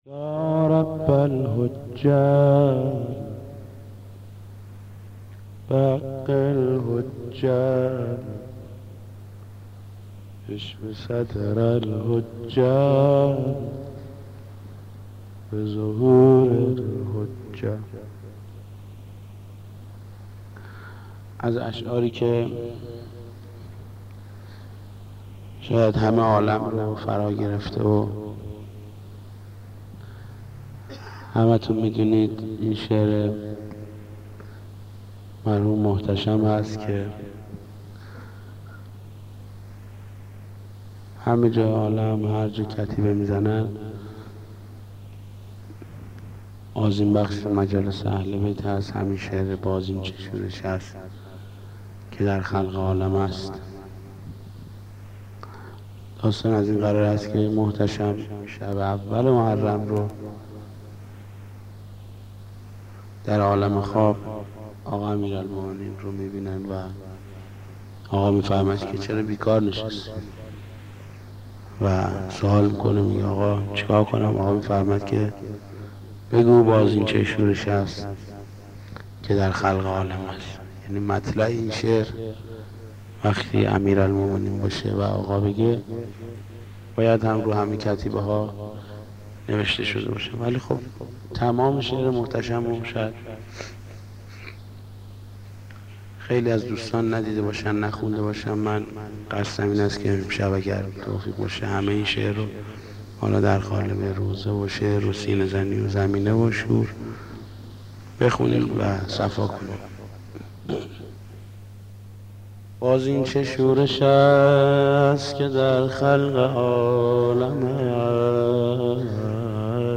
مداح
مناسبت : شهادت حضرت زین‌العابدین
مداح : محمود کریمی